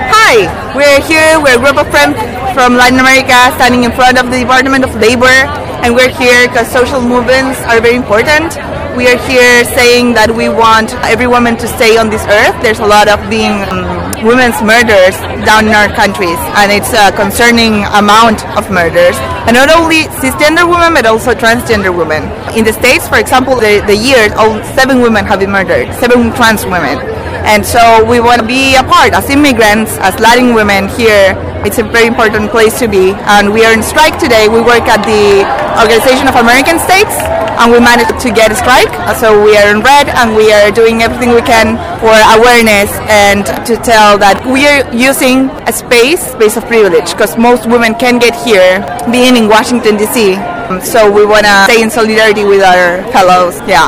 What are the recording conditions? Among the marchers, women from Latin America talked to us in front of the Department of Labor where the march started.